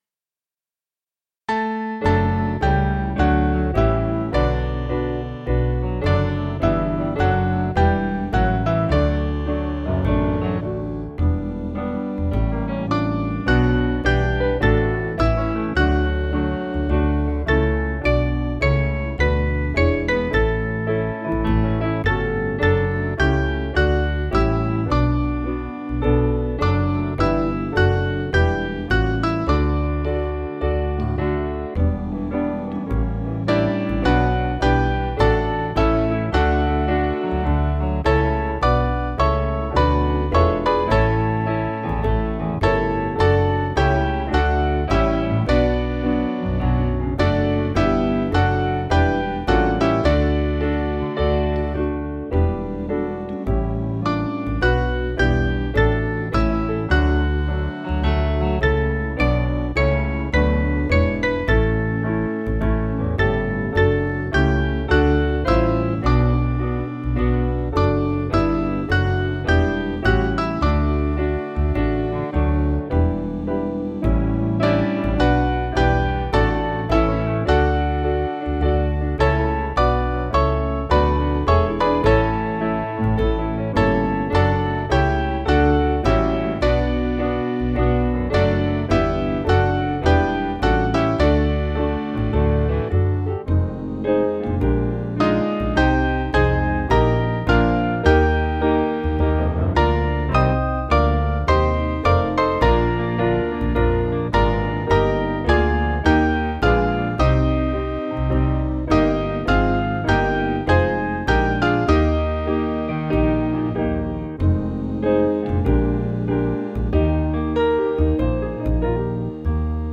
Mainly Piano
(CM)   5/D-Eb 475.6kb